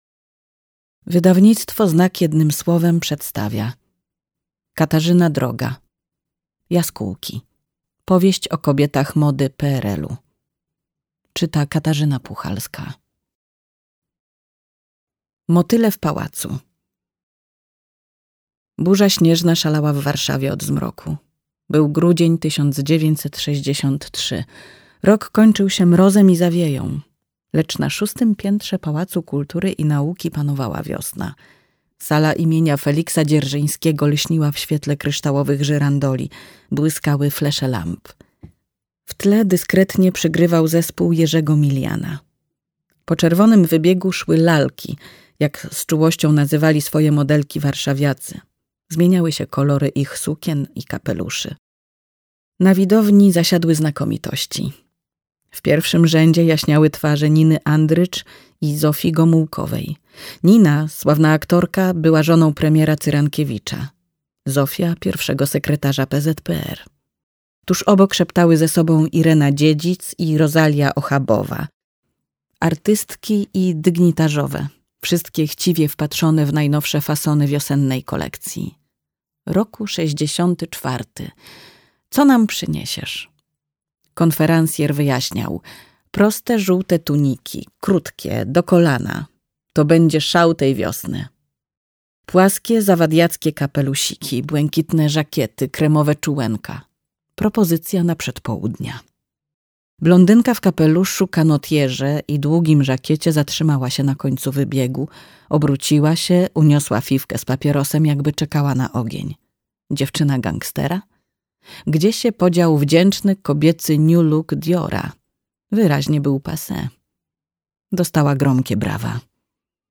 Jaskółki. Powieść o kobietach mody PRL-u - Katarzyna Droga - audiobook